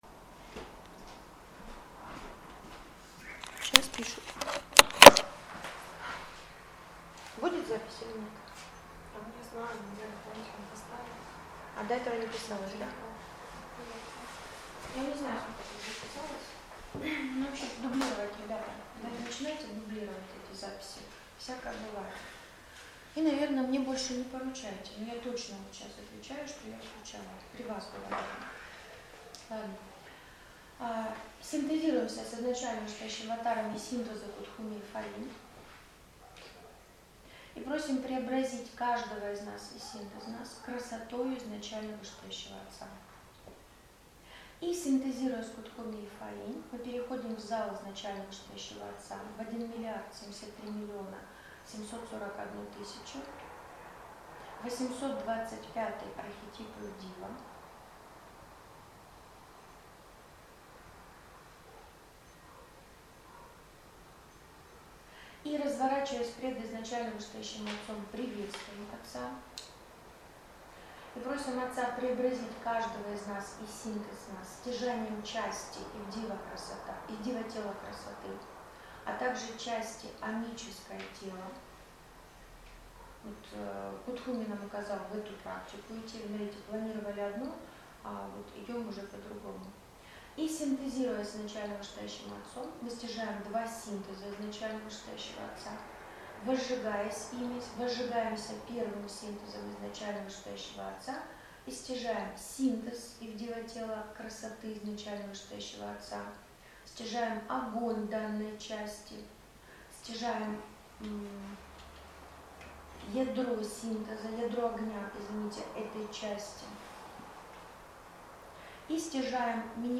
Тексты Слушать/смотреть Аудио/видео скачать Практики: DOCX Часть 2: Часть 3: Часть 4: Часть 2: Аудио Часть 3: Аудио Часть 4: Аудио По техническим причинам отсутствует аудио 1 части и запись 3 части фрагментарно 2 часть 01:44:51-01:58:24 Практика 1. Разработка архетипической Части Ум каждому из нас.